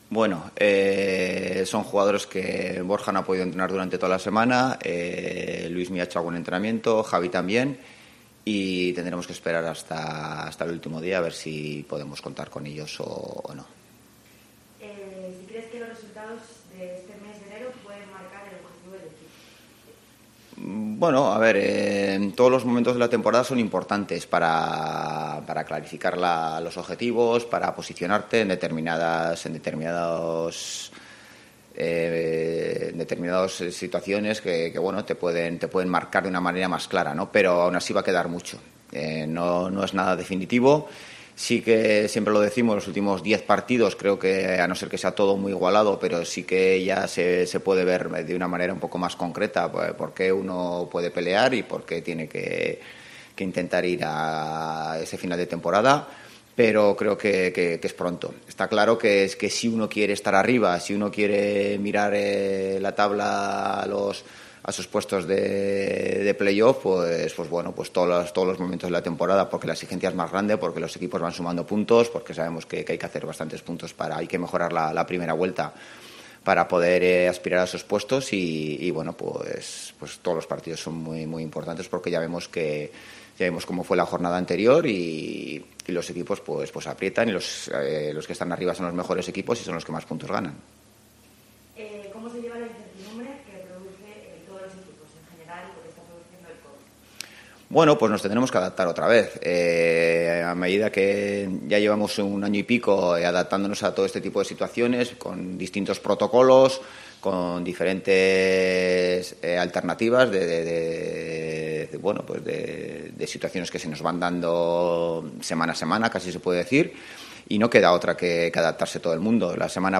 Rueda de prensa Ziganda (previa Eibar)